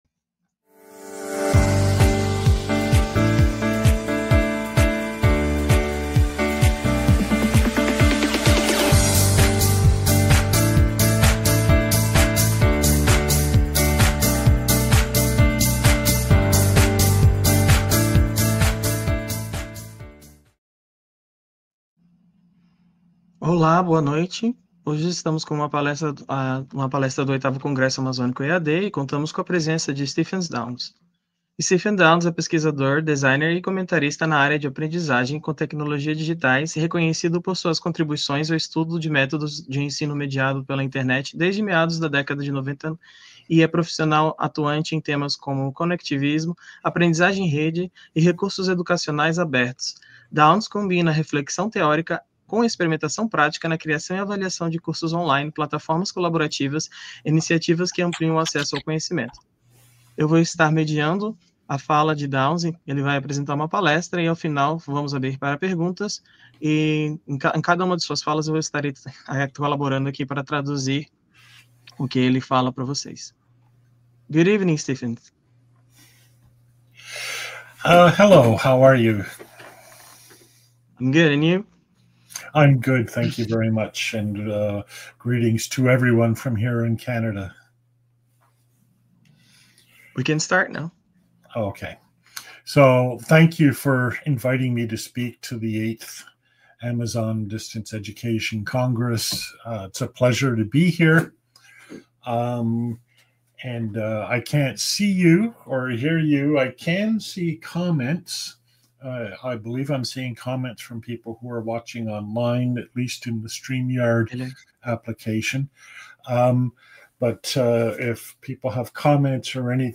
(Old style) [ Audio ] [ Video ] (New Style) [ PDF ] [ PPTx ] [ Audio ] [ Transcript ] VIII Congresso Amazônico de Educação a Distância, Instituto Federal de Rondônia, Rondônia, Brasil, via Streamyard, Keynote, Nov 28, 2025.